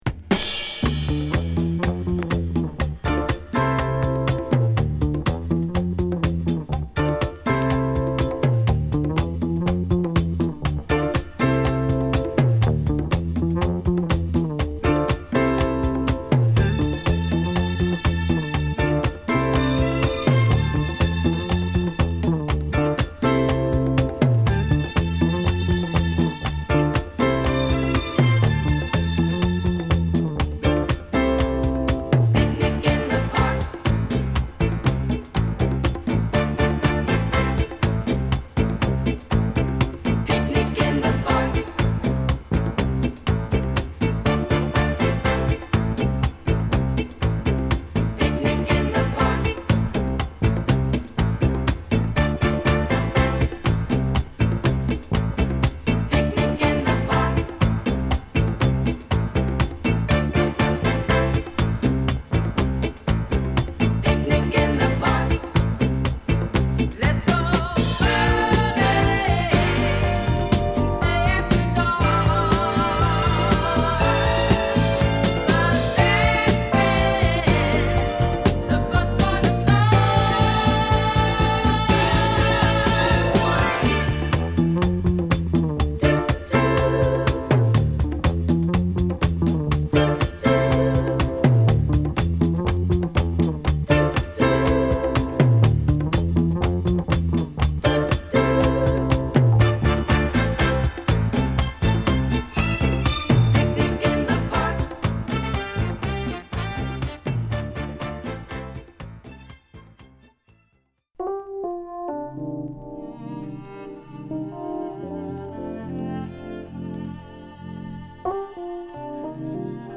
ダンサブルなフィリーサウンドで仕立てたジャズスタンダード